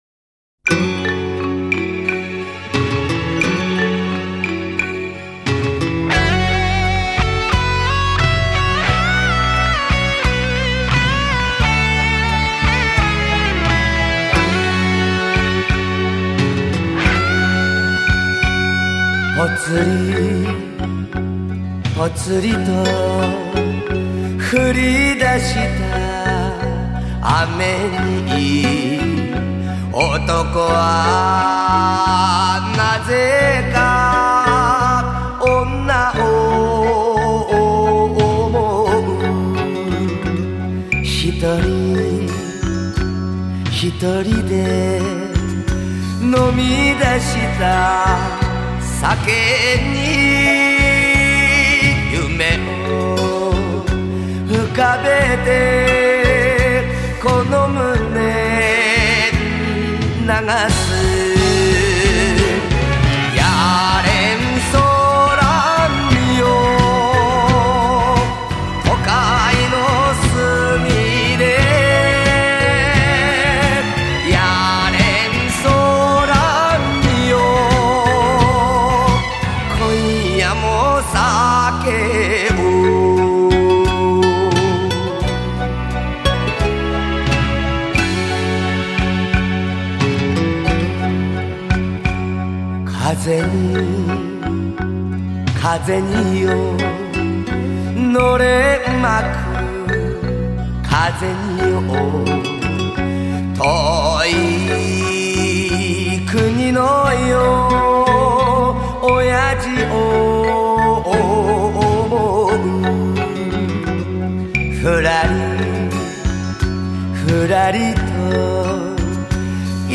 好听的男音，谢谢